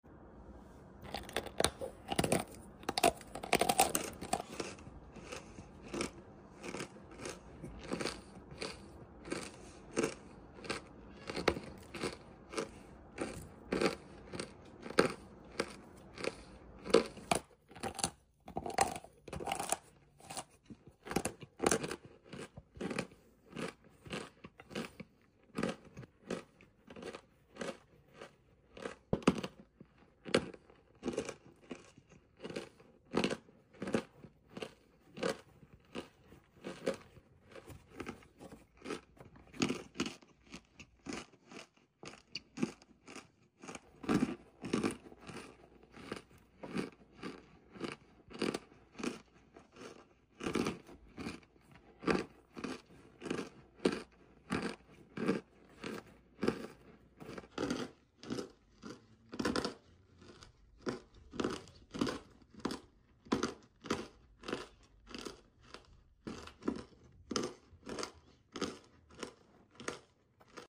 Lazy Edit But The Crunch Sound Effects Free Download